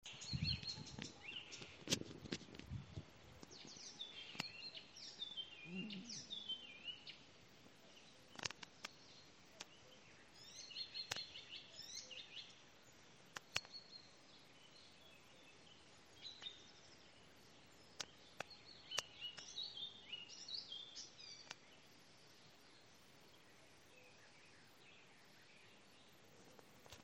пересмешка, Hippolais icterina
СтатусПоёт
Примечанияceru, ka trāpīju. ieraksti gan klusi